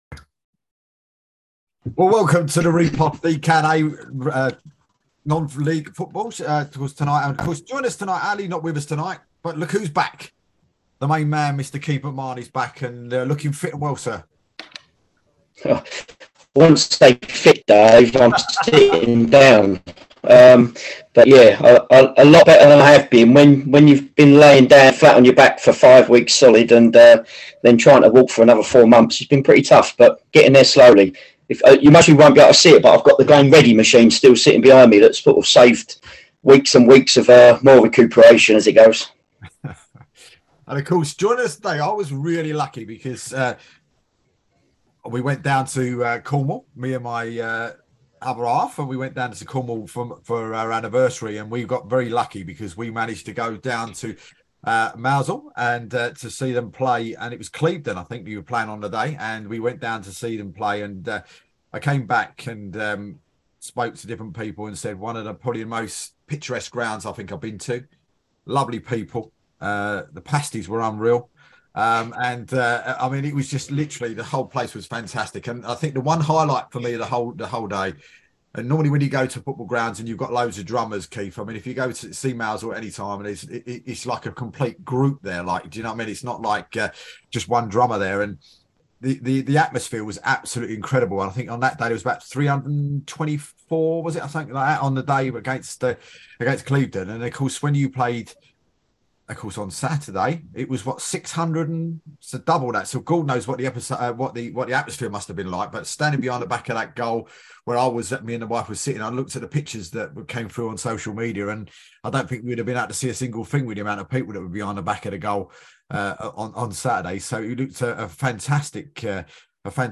apologies cut off at 45 mins app due to power cut